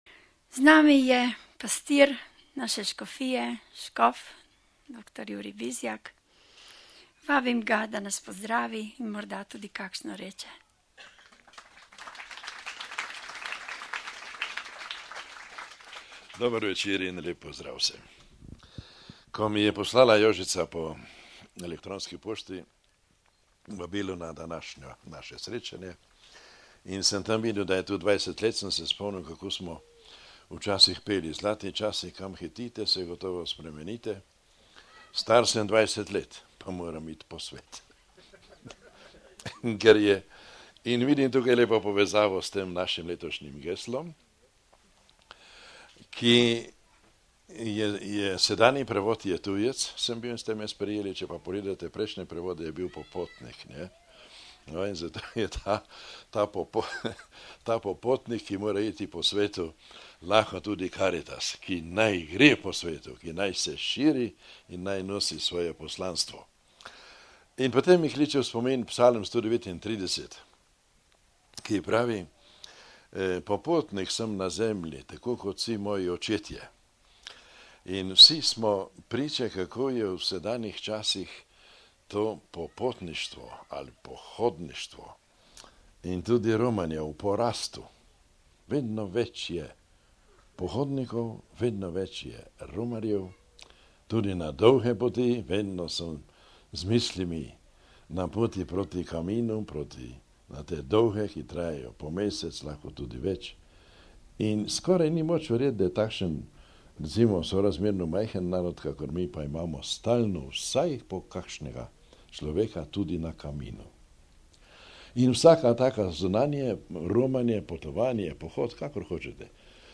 Ob 18. uri je bila sklepna prireditev s predstavitvijo nastalih del in kulturnim programom.
Audio nagovora škofa Jurija Bizjaka: RealAudio